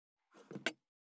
201,工具,小物,雑貨,コトン,トン,ゴト,ポン,ガシャン,ドスン,ストン,カチ,タン,バタン,スッ,サッ,コン,ペタ,パタ,
コップ効果音物を置く
効果音